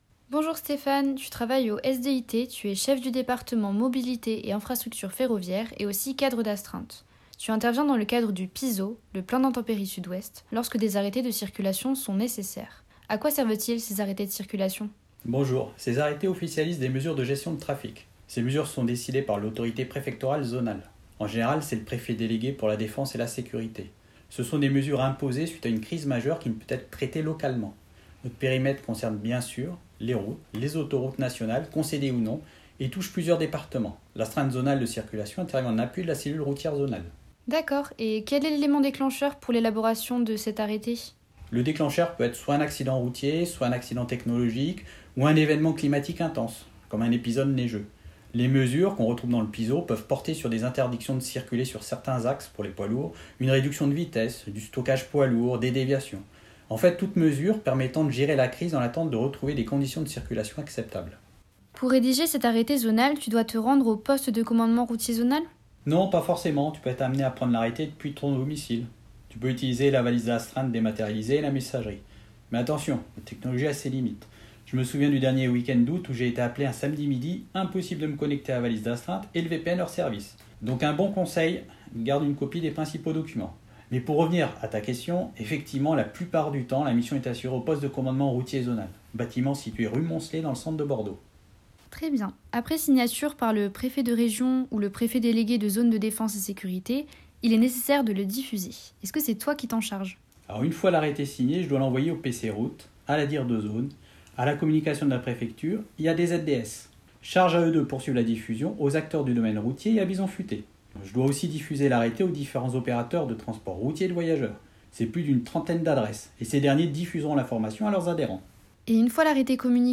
Retrouvez deux interviews de cadres d’astreinte :